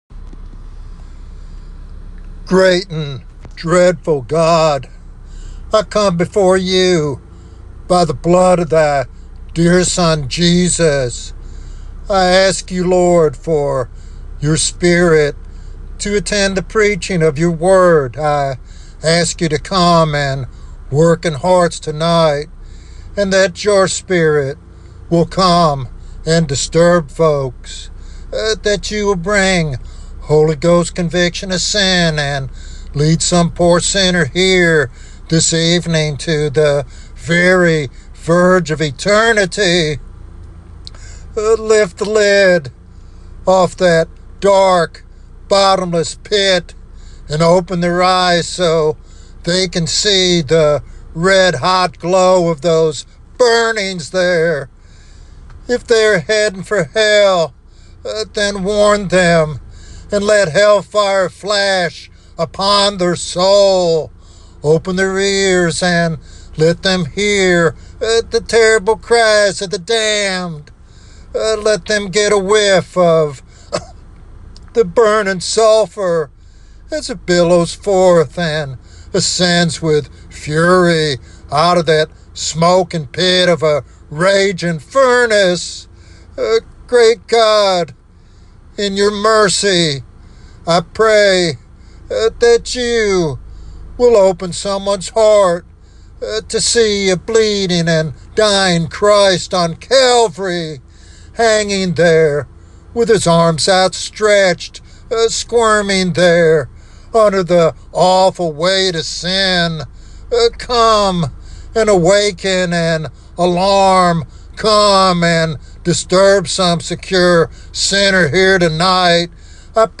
This sermon challenges complacency and urges urgent repentance and faith in the Savior. It is a solemn yet hopeful proclamation of the gospel’s power to save from eternal damnation.